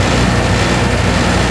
turbine.wav